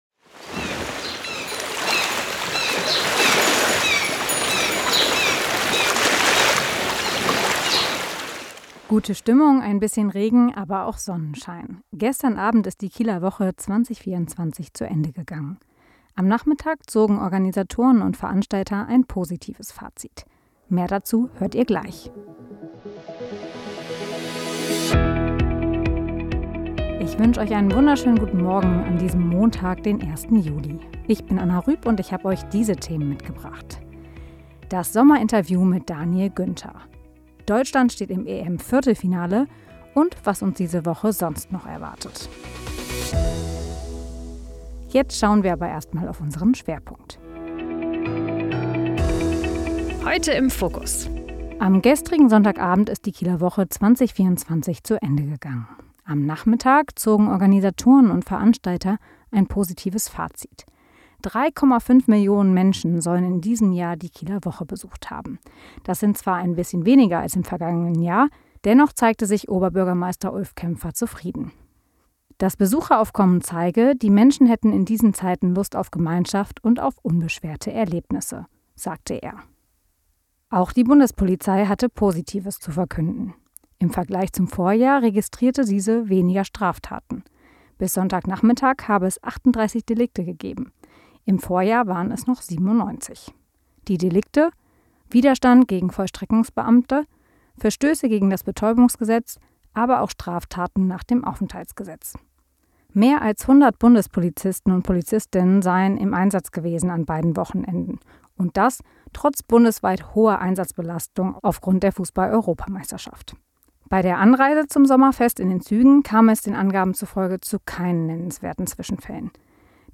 Dein News-Podcast für Schleswig-Holstein
Nachrichten